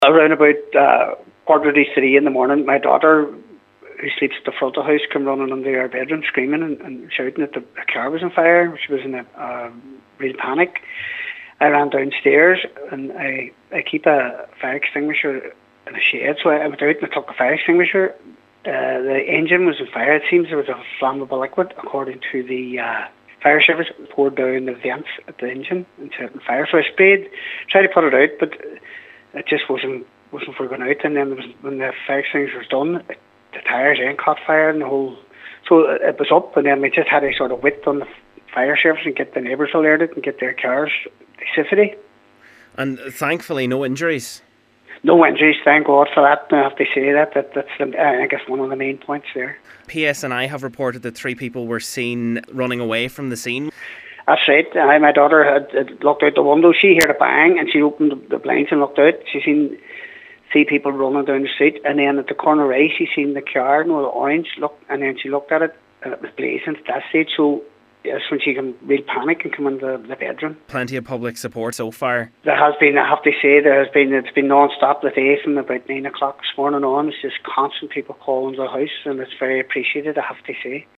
Cllr. Campbell has thanked people for the support shown to him and his family following the incident: